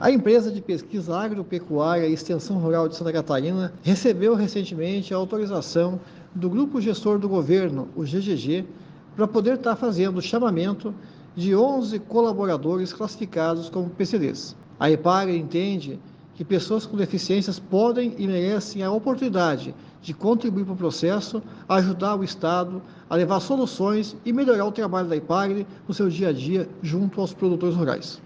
Segundo o presidente Dirceu Leite, com a presença de novos empregados com deficiência, a Epagri assume a responsabilidade social de dar oportunidade para que esses profissionais  desenvolvam suas potencialidades como também promove uma cultura organizacional mais inclusiva, em que todos os funcionários são respeitados: